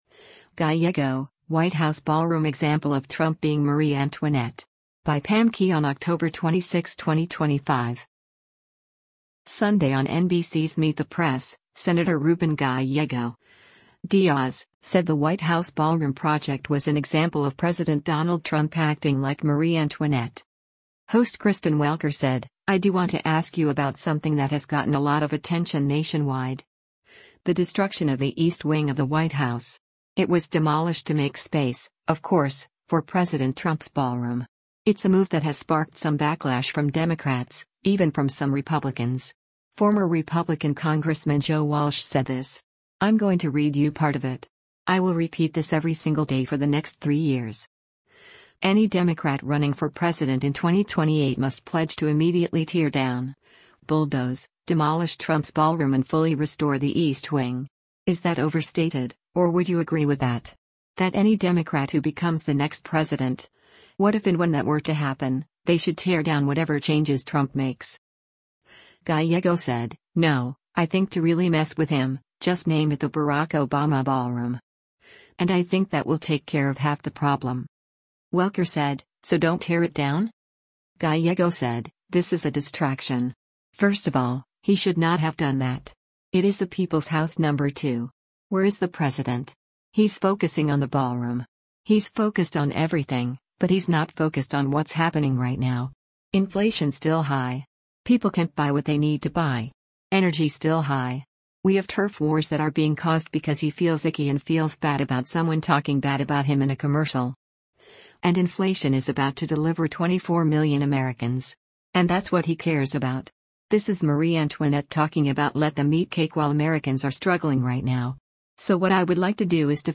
Sunday on NBC’s “Meet the Press,” Sen. Ruben Gallego (D-AZ) said the White House ballroom project was an example of President Donald Trump acting like Marie Antoinette.